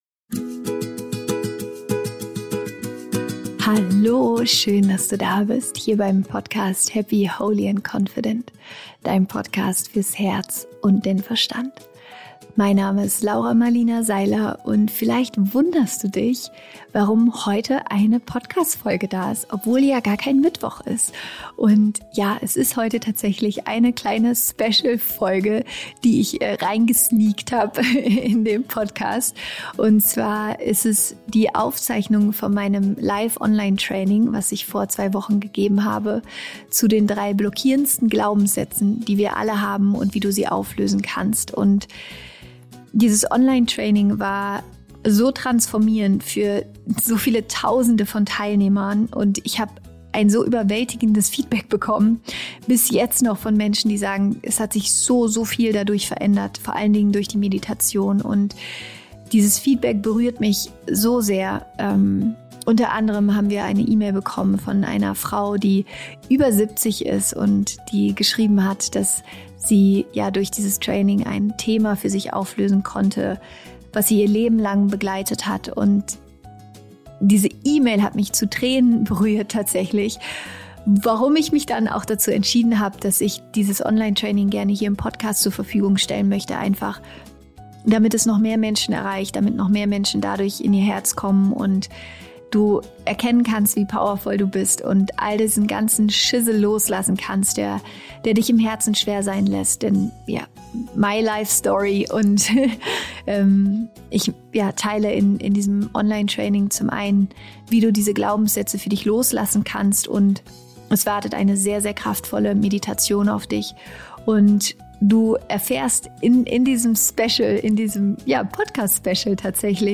Ende November habe ich mit tausenden von Menschen live ein super inspirierendes und transformierendes Online Training gegeben und wir haben so überwältigendes Feedback von den Teilnehmern bekommen.